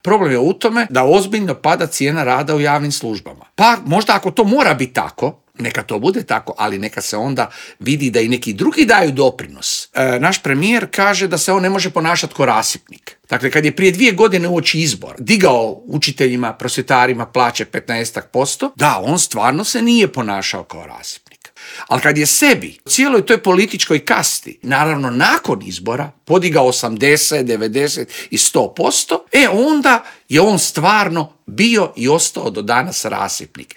intervjuu